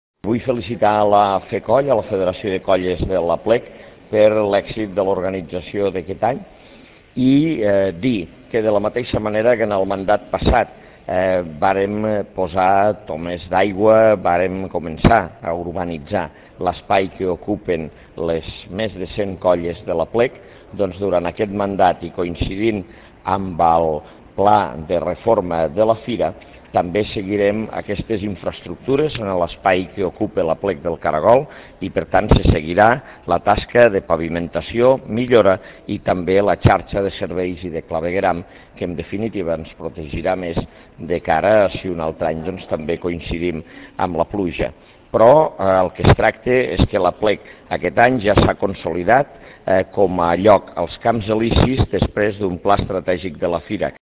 ARXIU DE VEU on Ros argumenta la previsió de la Paeria de continuar amb la millora de l'espai de l'Aplec
arxiu-de-veu-on-ros-argumenta-la-previsio-de-la-paeria-de-continuar-amb-la-millora-de-lespai-de-laplec